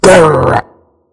infinitefusion-e18/Audio/SE/Cries/HOUNDOUR.mp3 at releases-April